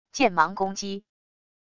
剑芒攻击wav音频